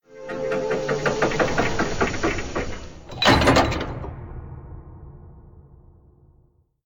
BigDoorOpen1.ogg